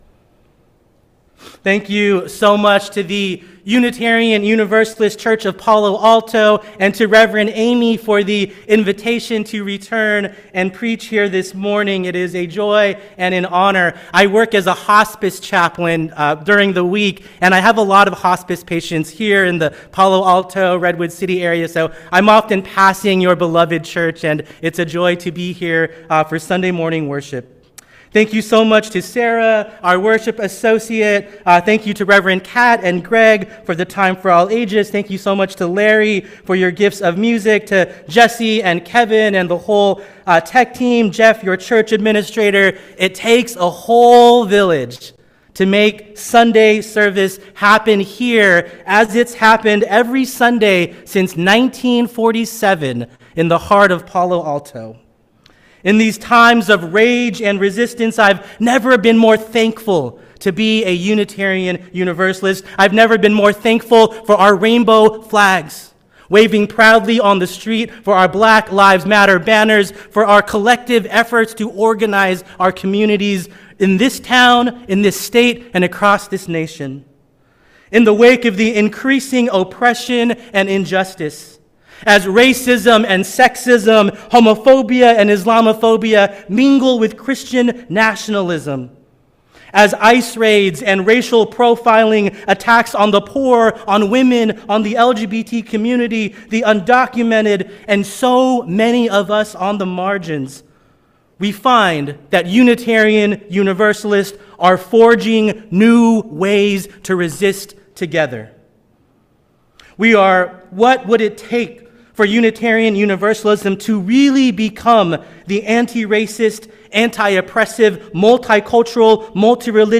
Sermons and Reflections